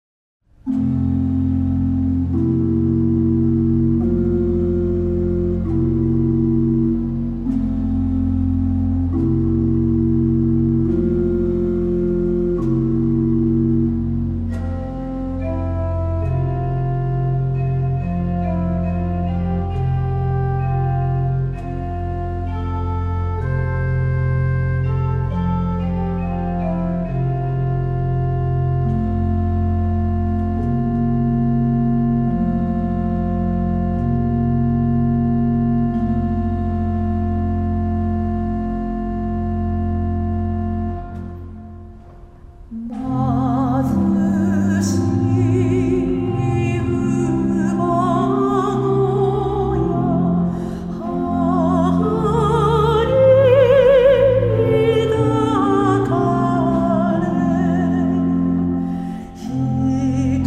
女声合唱
混声合唱